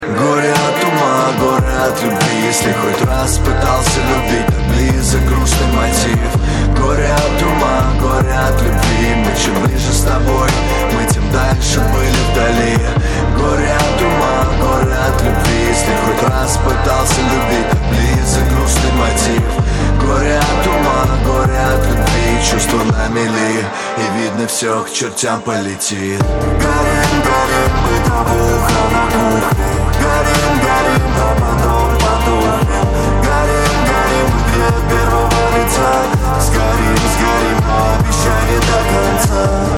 мужской вокал
лирика
русский рэп